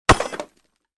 Звуки лома
Бросили к балкам